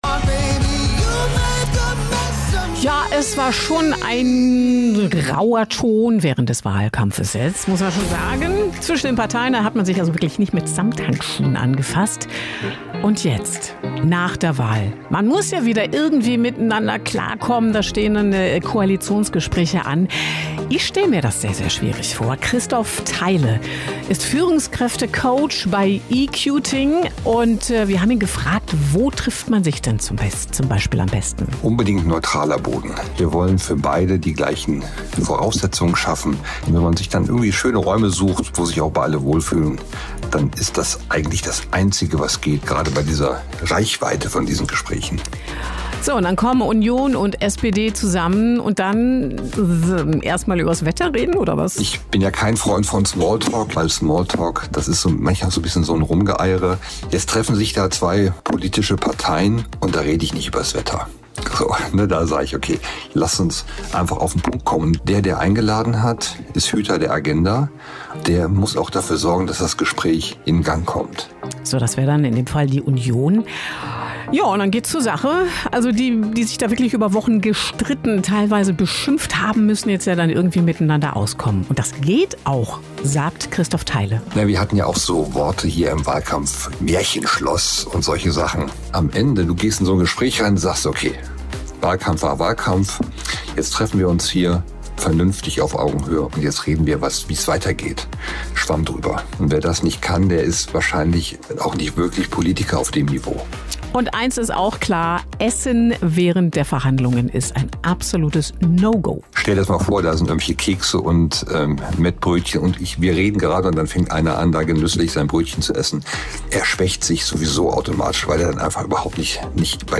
🎙 On Air bei Radio NRW – was für ein Highlight!
RadioNRW_Interview_Verhandlung.mp3